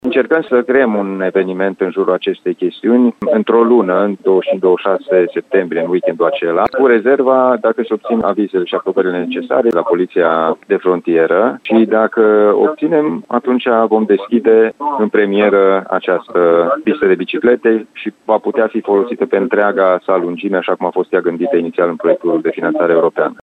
Momentan, sunt așteaptate autorizațiile necesare de la poliția de frontieră din cele două țări, a anunțat președintele Consiliului Județean Timiș, Alin Nica.